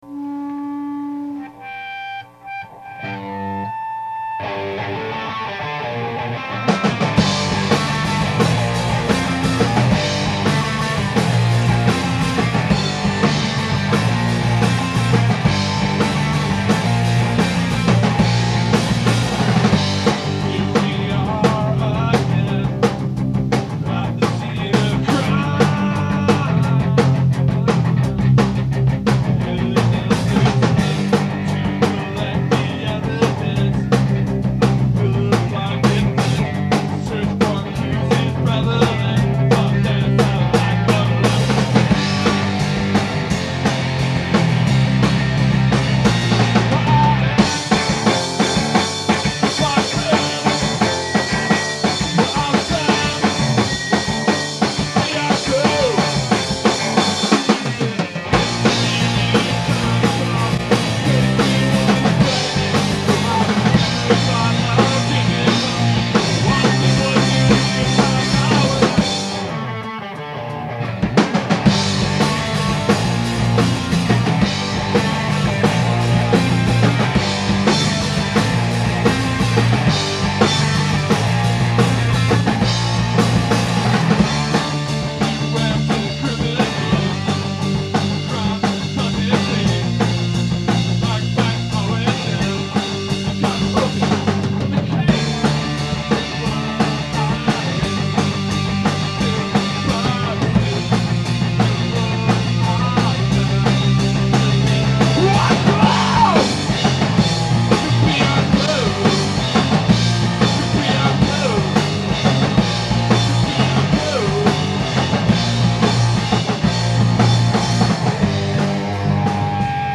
Recorded May 17, 2003 at Babylon Night Club